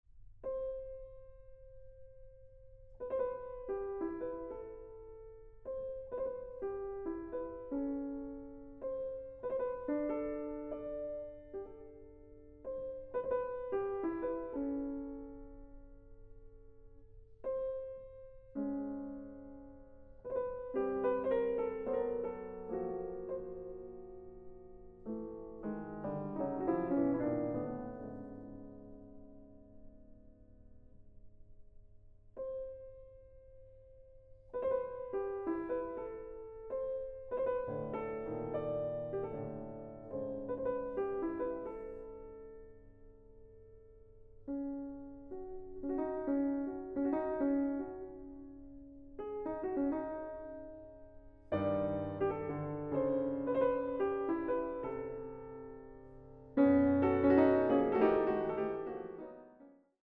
Klavier
Aufnahme: Mendelssohnsaal, Gewandhaus Leipzig